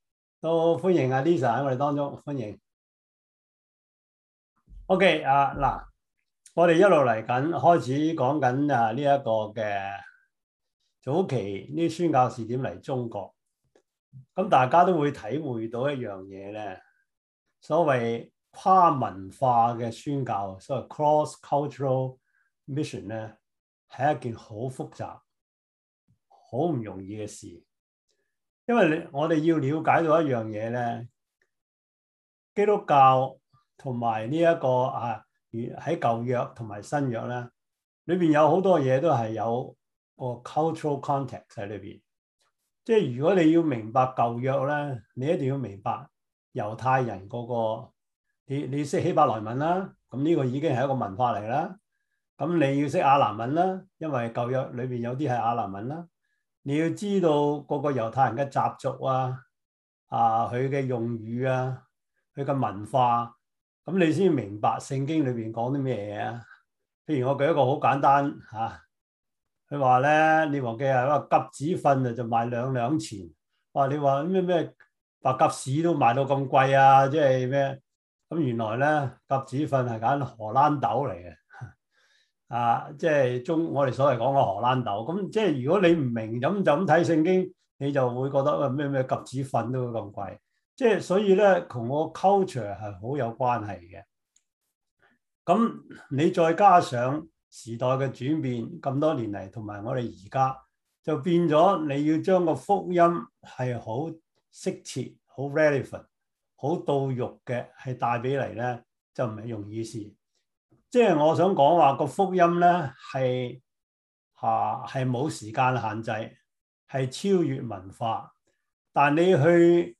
Service Type: 中文主日學